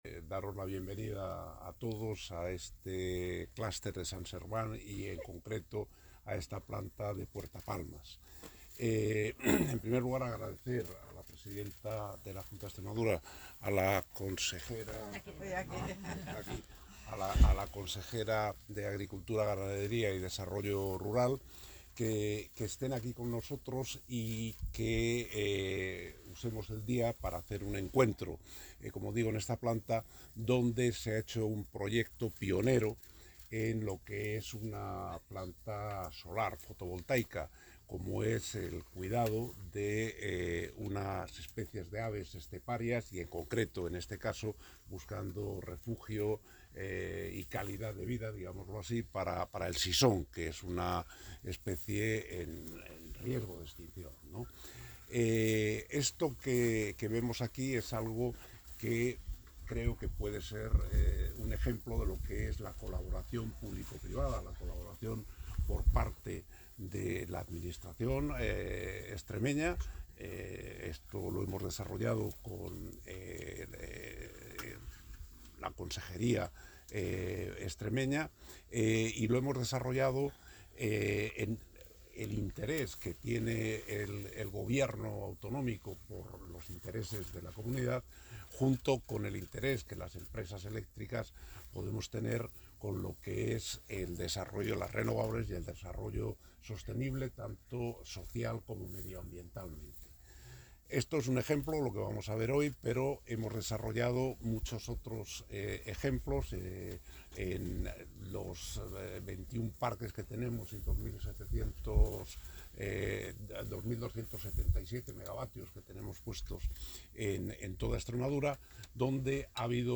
Declaraciones de José Bogas, Consejero Delegado de Endesa